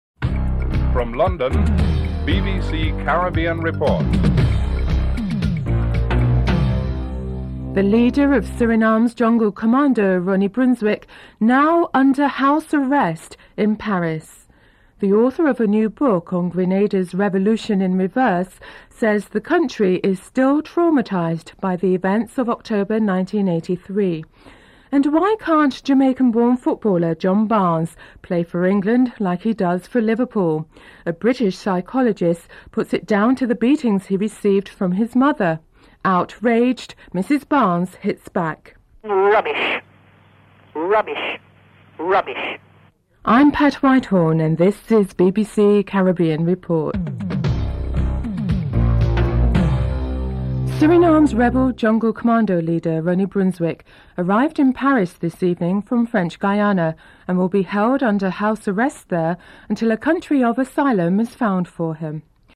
1. Headlines (00:00-00:49)
3. Financial News (04:04-05:47)